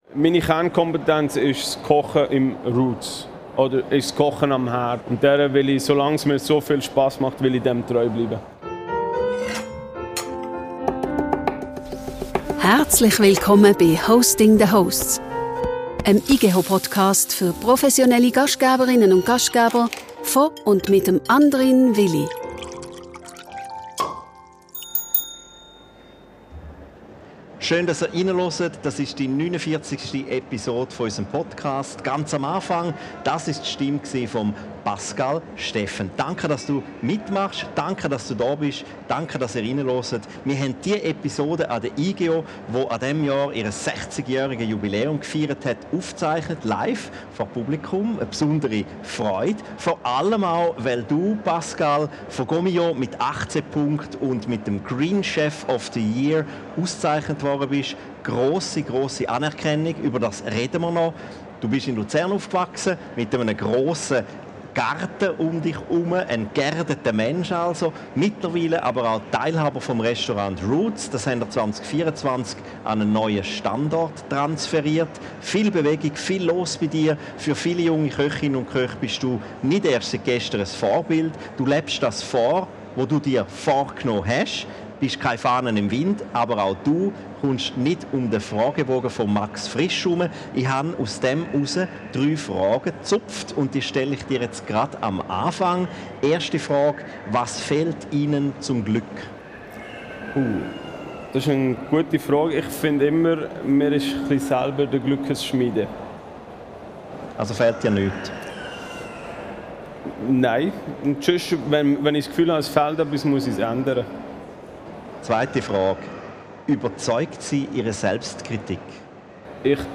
in den Cheminée Chats der Igeho 2025.